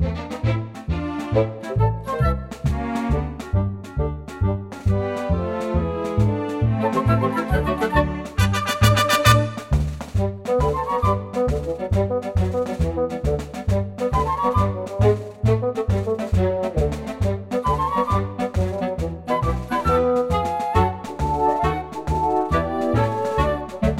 no Backing Vocals Musicals 3:51 Buy £1.50